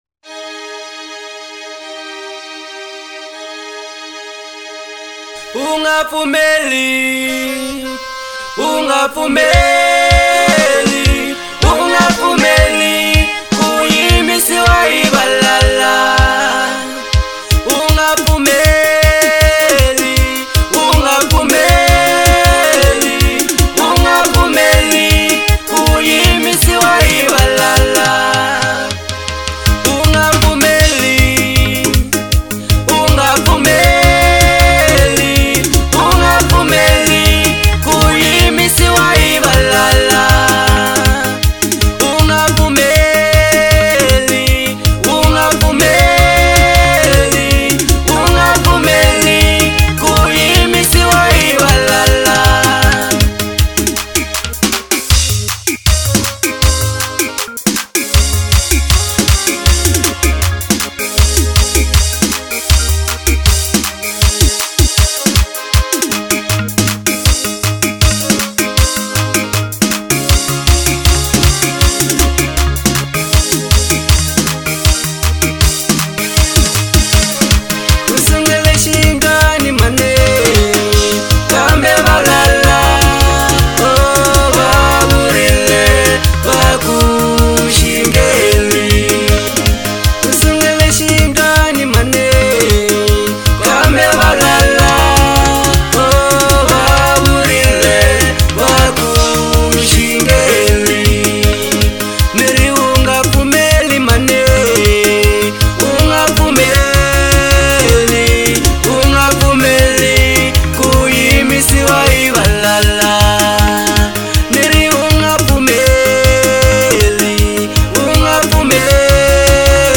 07:03 Genre : Xitsonga Size